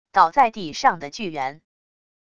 倒在地上的巨猿wav音频